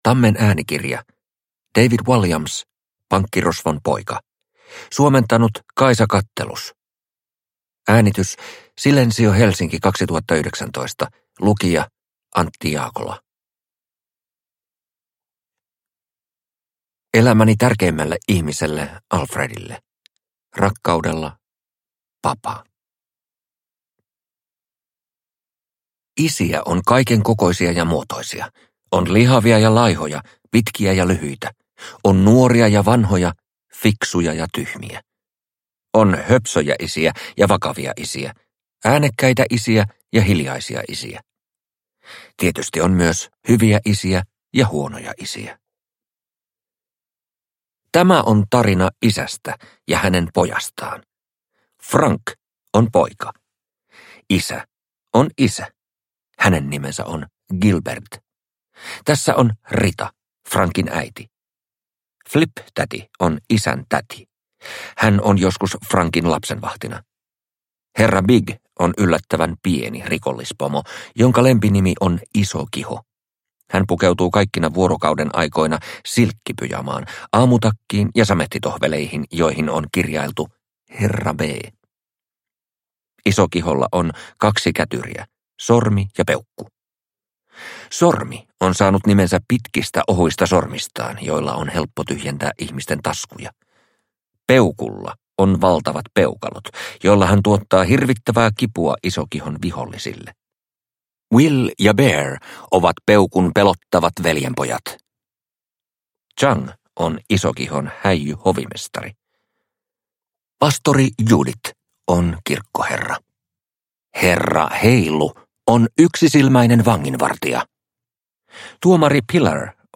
Pankkirosvon poika – Ljudbok – Laddas ner